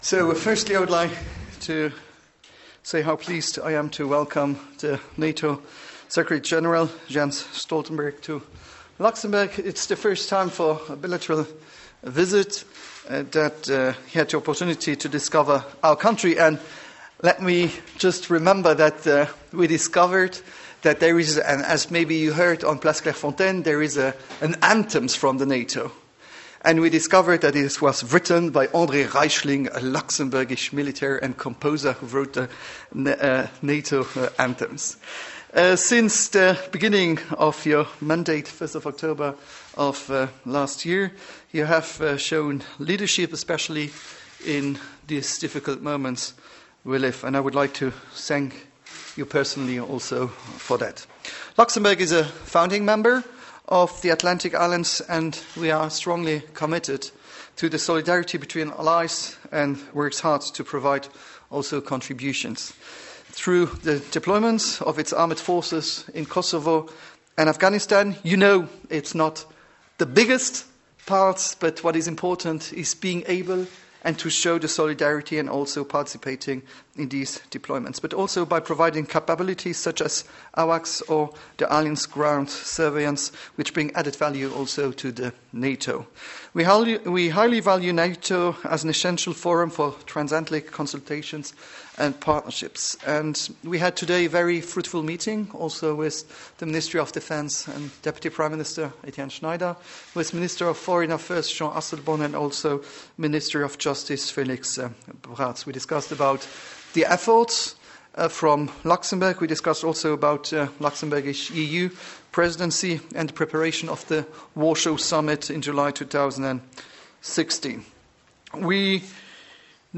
NATO Secretary General Jens Stoltenberg thanked Prime Minister Xavier Bettel for Luxembourg’s political, military, and financial contributions to the Alliance in a visit to the Grand Duchy on Wednesday (2 September 2015). In a joint press conference with Prime Minister Bettel, Mr. Stoltenberg thanked Luxembourg for being a reliable Ally, and welcomed the country’s role in strengthening the Alliance’s shared security.